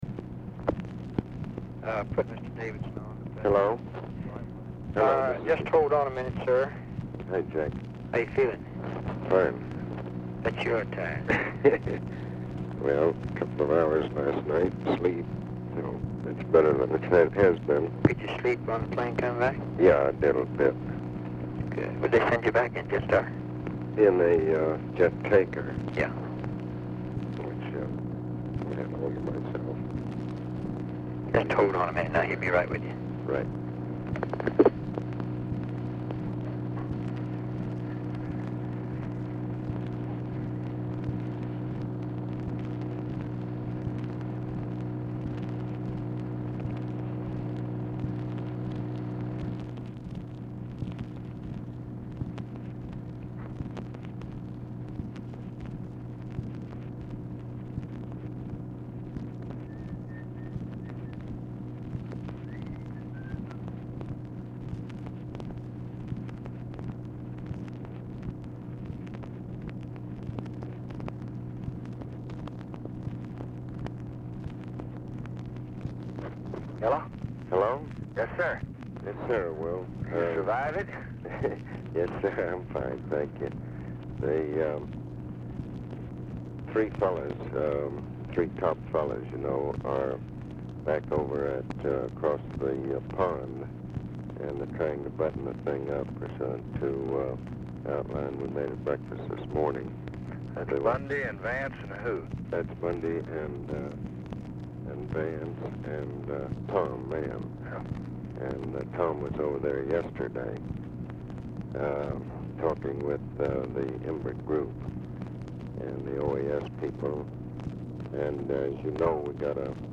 Telephone conversation # 7700, sound recording, LBJ and ABE FORTAS, 5/16/1965, 12:30PM | Discover LBJ
JACK VALENTI SPEAKS WITH FORTAS BEFORE LBJ TAKES CALL; POOR SOUND QUALITY AT TIMES
Format Dictation belt
Location Of Speaker 1 Camp David, Catoctin Mountain Park, Maryland